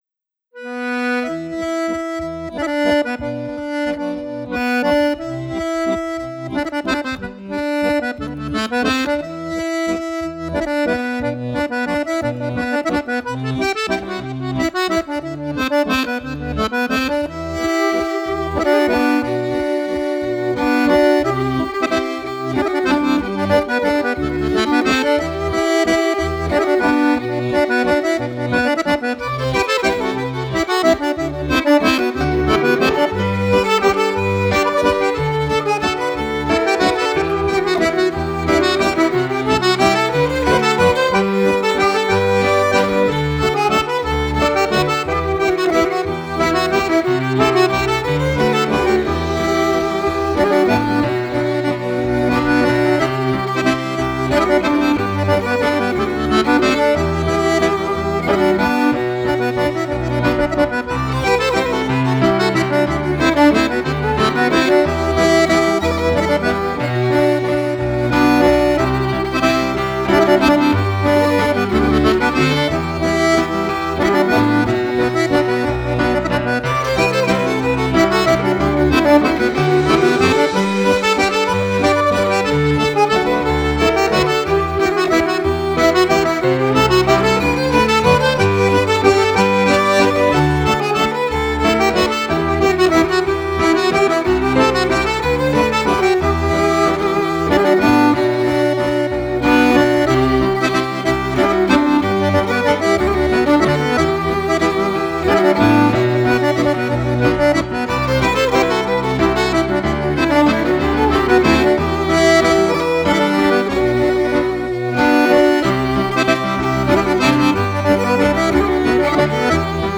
violon
cajon, caisse claire
cornemuse irlandaise, flûtes
piano